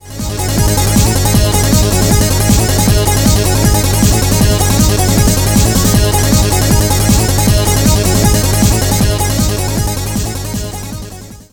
マスタリングしたBGM
（ご注意！：他の2つよりはるかに音量が大きいので注意してくださいね。）